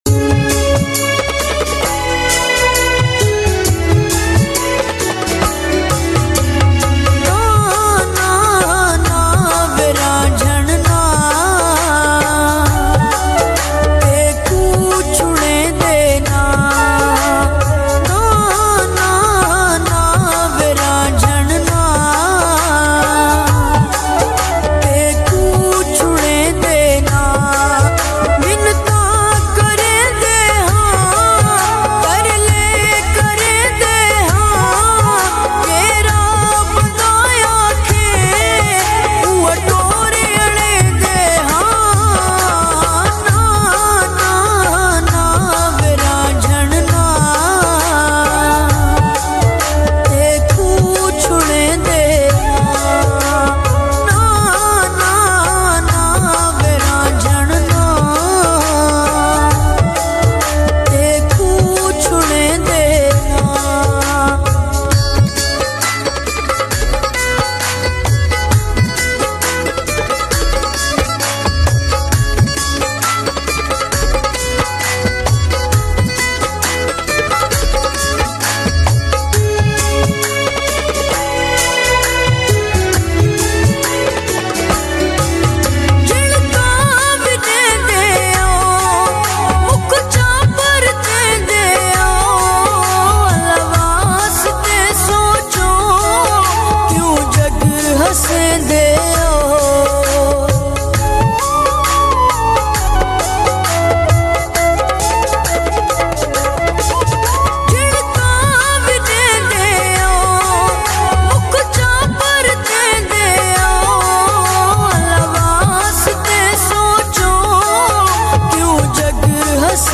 saraiki treding full song
bass boostedmusic